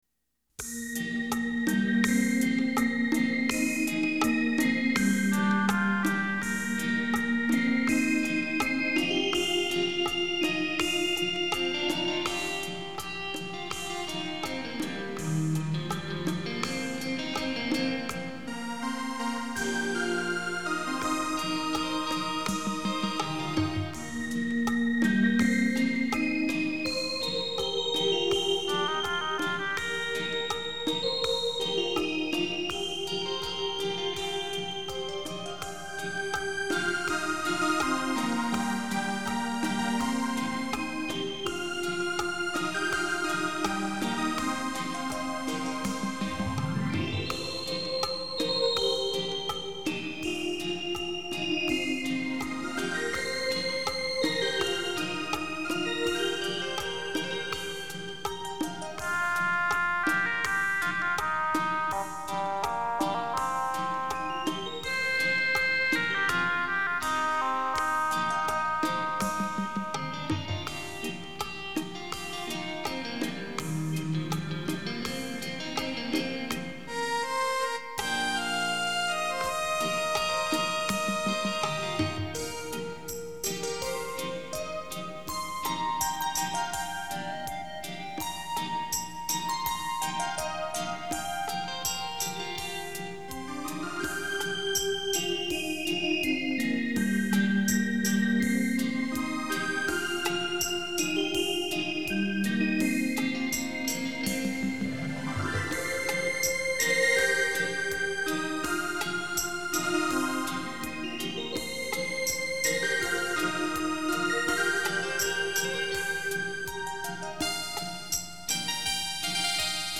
再收一集绝美的电子琴音乐，跟着版主一起回味昔日的浪漫，感受音乐带来的惬意！
休闲放松的音乐